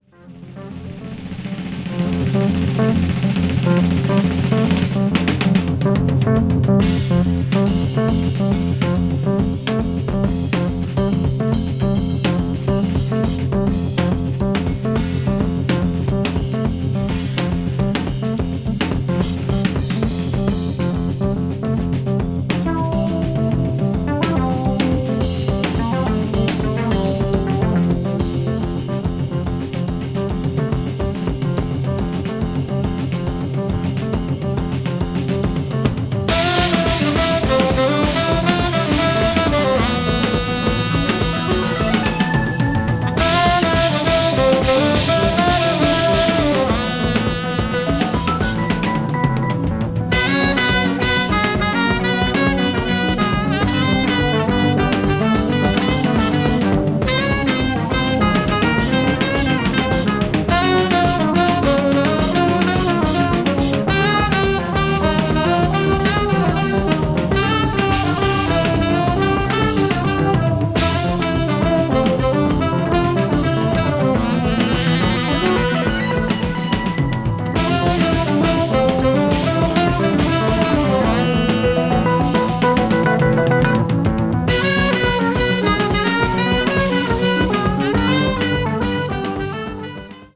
media : EX+/EX+(わずかにチリノイズが入る箇所あり)
凄まじいテンションで疾走する後半のピアノの上昇具合がヤバいB1も最高です。